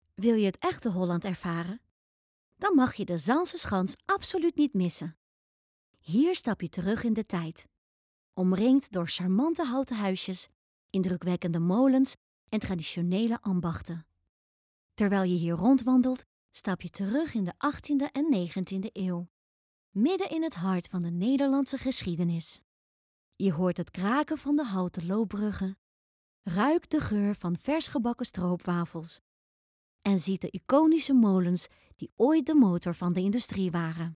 Opvallend, Speels, Veelzijdig, Vriendelijk, Warm
Audiogids